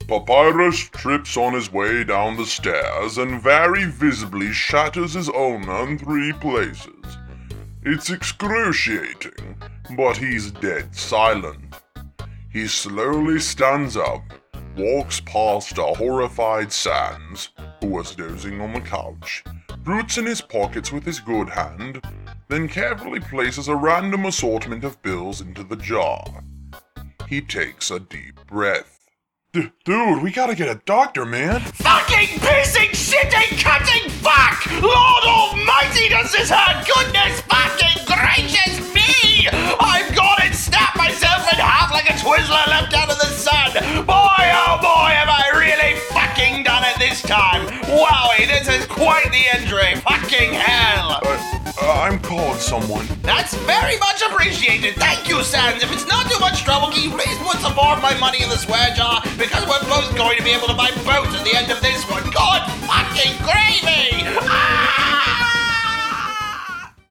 And yes, that IS my actual Asgore voice narrating.